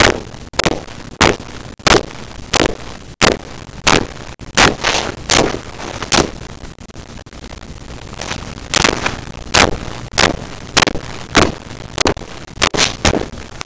Footsteps Linoleum Slow 01
Footsteps Linoleum Slow 01.wav